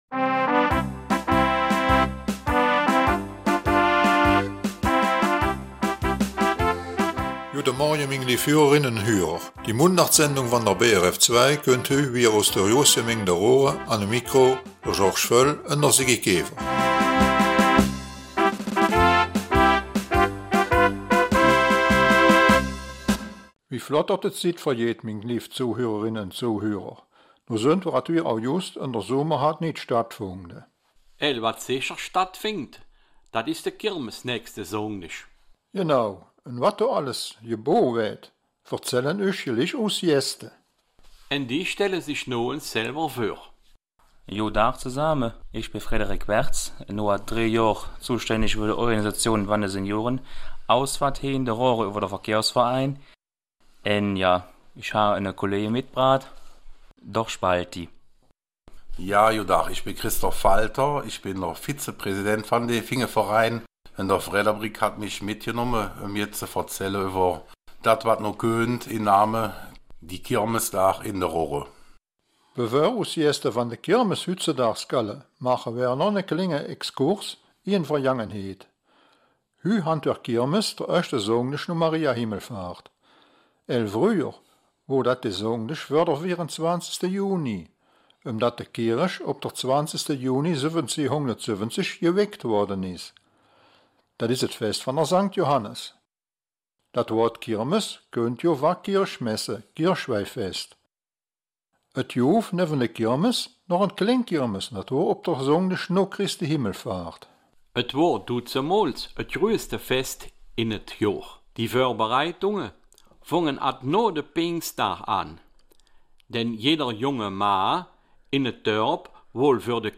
Raerener Mundart - 11. August